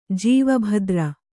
♪ jīva bhadra